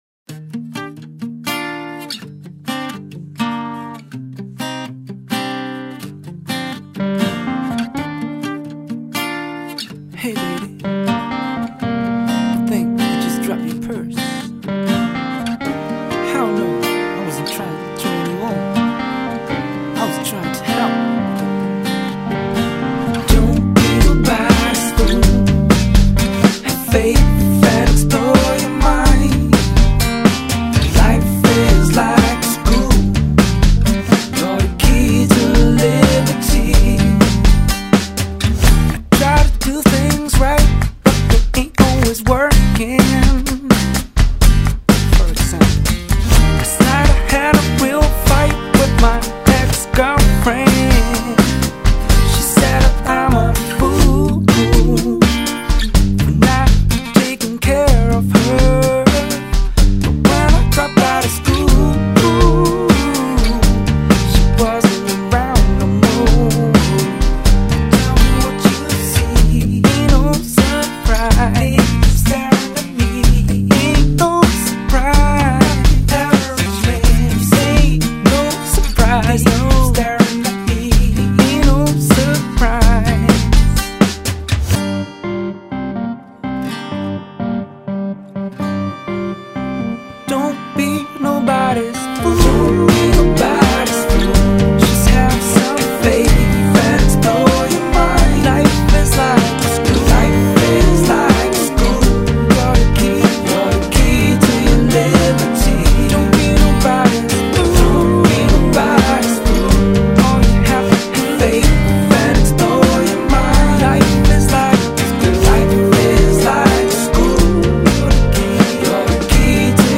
Soul / electronica.